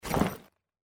ui_interface_149.wav